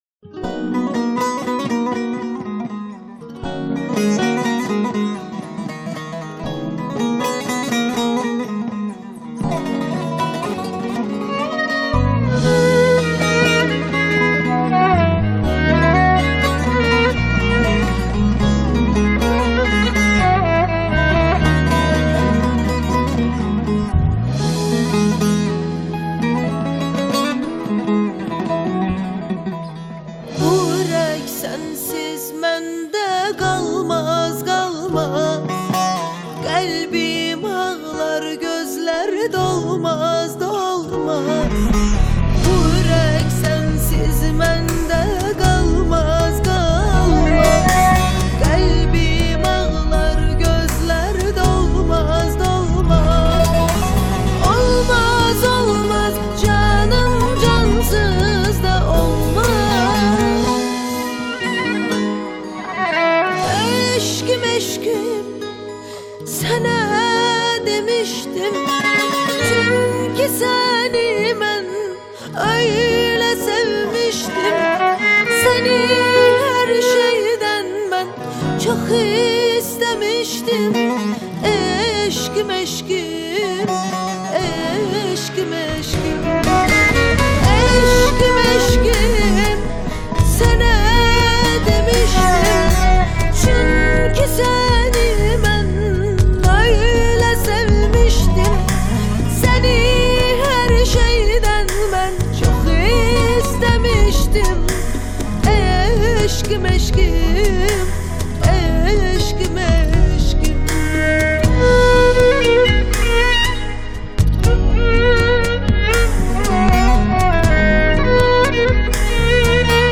آهنگ آذربایجانی